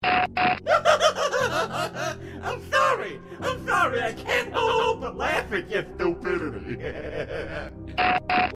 Lolbit Voice Line Laugh At Your Stupidity